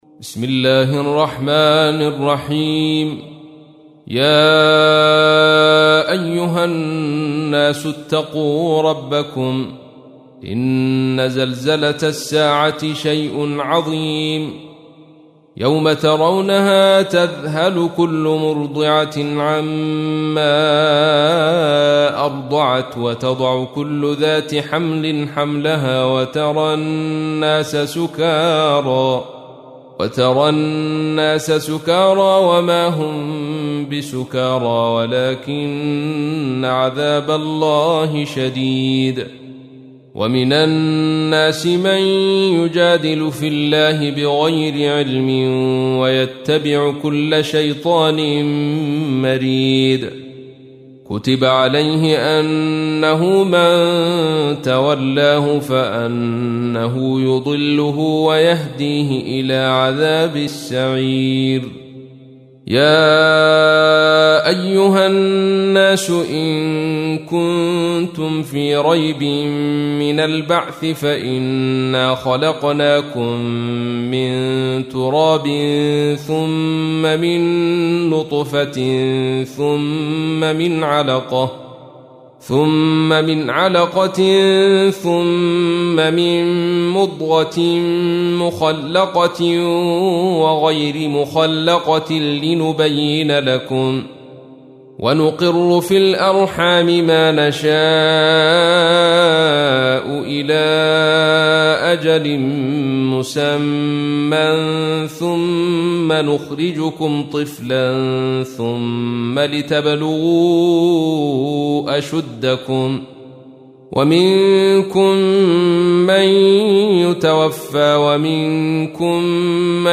تحميل : 22. سورة الحج / القارئ عبد الرشيد صوفي / القرآن الكريم / موقع يا حسين